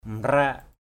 /mra:ʔ/ (d.) sơn = peindre. paint. aia mrak a`% mK nước sơn = peinture. paint.